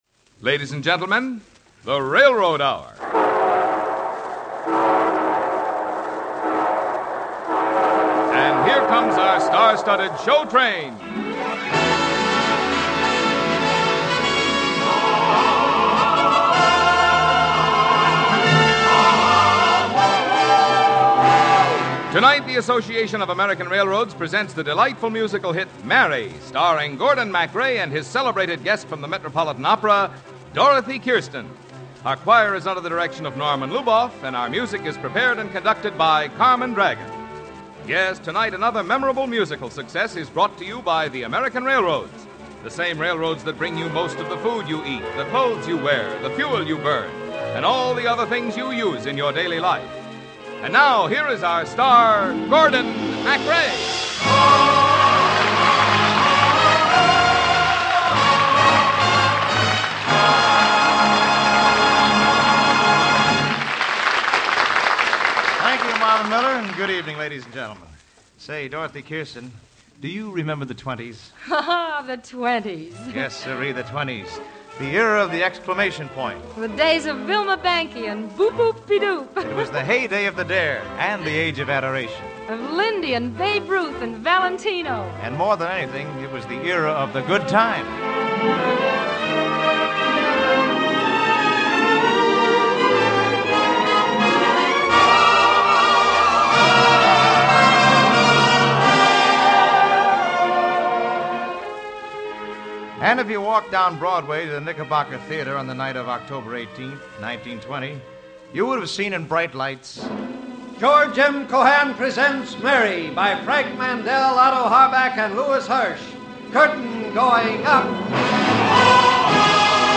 The Railroad Hour was a delightful radio series that aired musical dramas and comedies from the late 1940s to the mid-1950s.